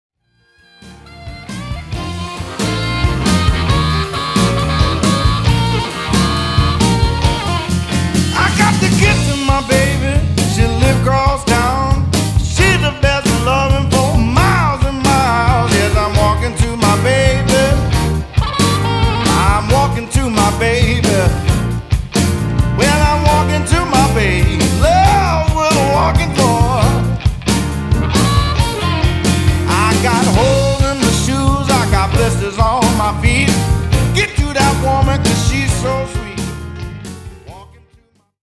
The theme for this release is Red Hot Guitar
energetic takes on
Vocal & Harmonica